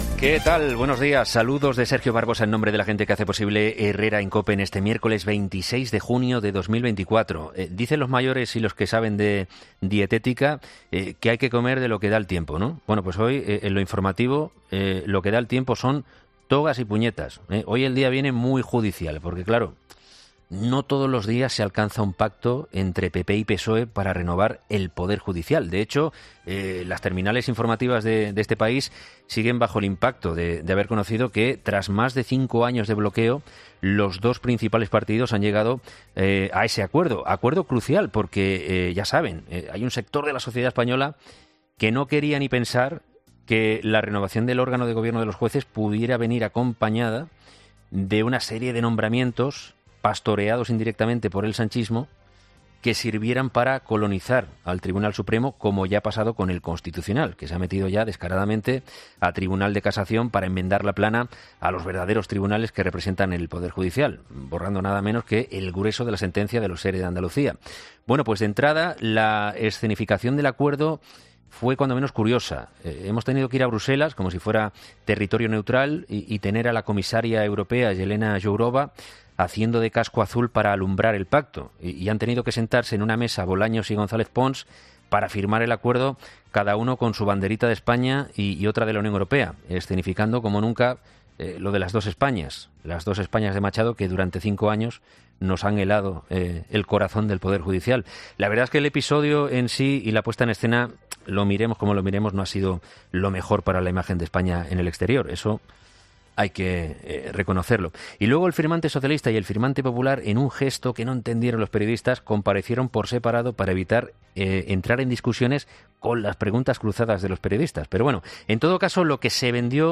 Luis del Val pone el foco de la imagen del día de "Herrera en COPE” en la ley de amnistía y los ERE de Andalucía: